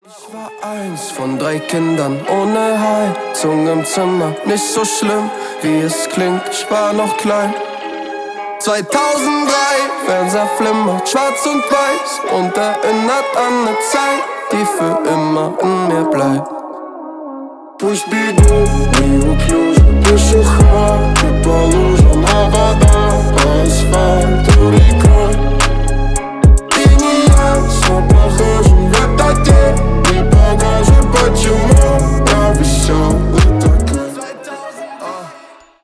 • Качество: 256, Stereo
рэп
Trap